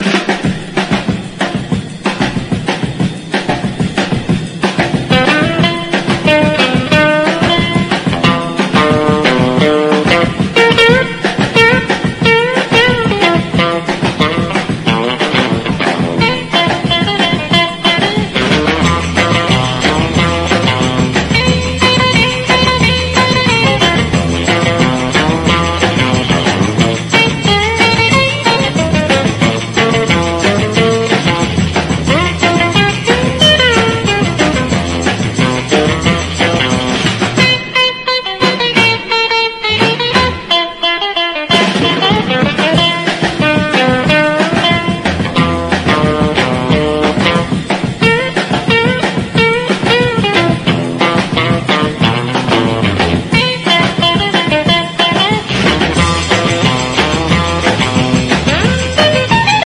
一味違うマイナーな個性派ヒルビリー/ロカビリー45を集めたコンピレーション！